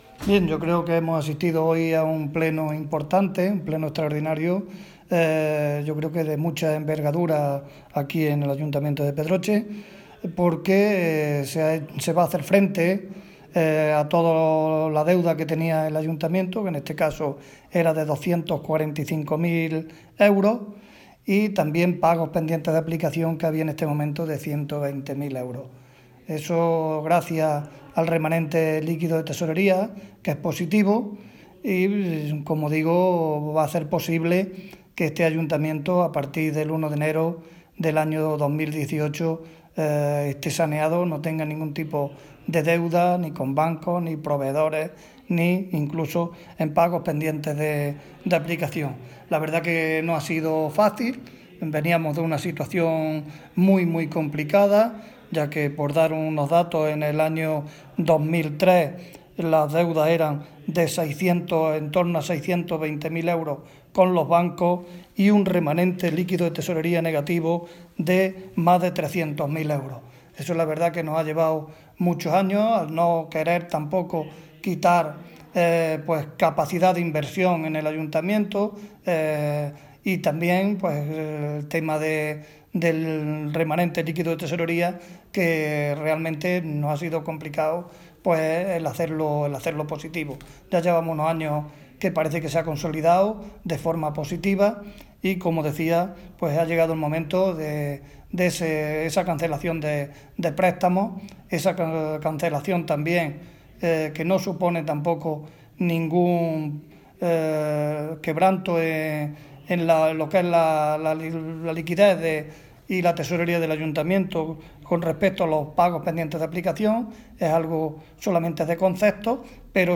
Escuchamos la valoración completa realizada por Santiago Ruiz tras la sesión del Pleno: